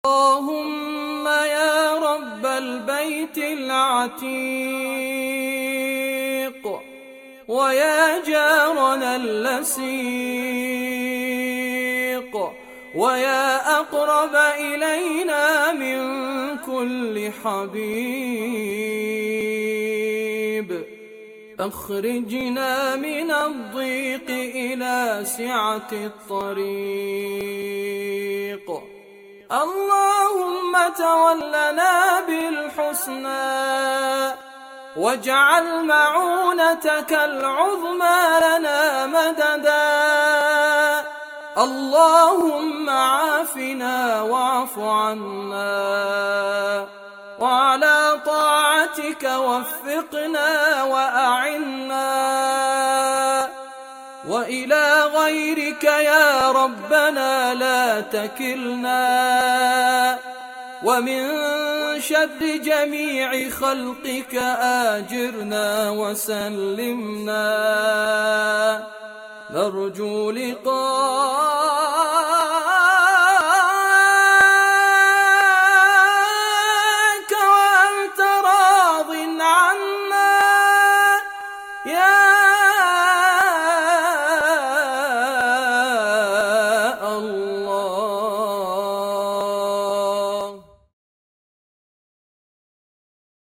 دعاء خاشع يتضرع فيه العبد إلى ربه، طالباً الهداية إلى الصراط المستقيم والعون والتوفيق. يعبر النص عن حالة من الأنس بالله واللجوء إليه، مع التوسل بأسمائه وصفاته لتحقيق المغفرة والنجاة.